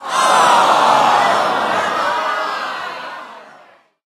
post_match_lose_cheer_01.ogg